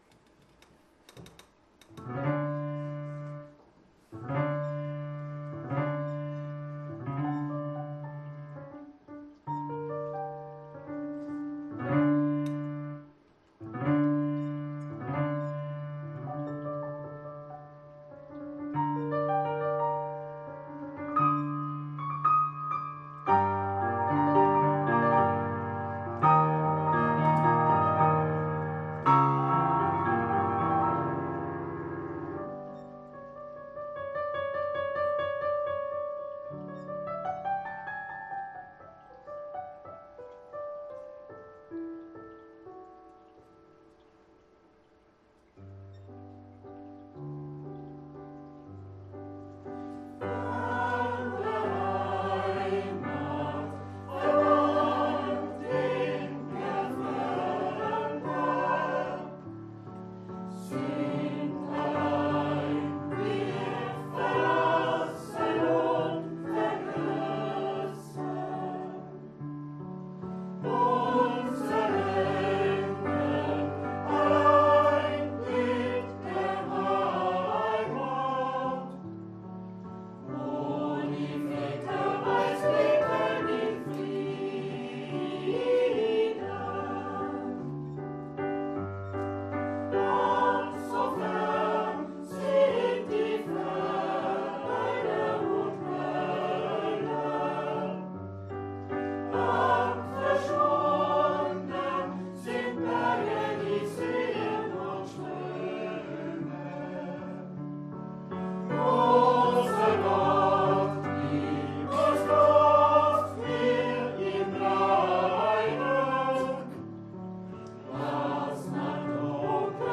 aus der Pfarrkirche Karnburg